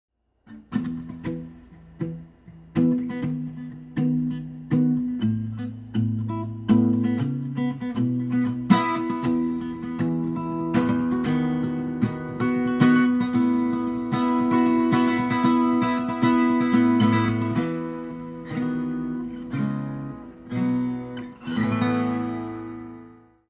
Just got a new guitar and I think shes jealous.